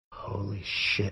Reactions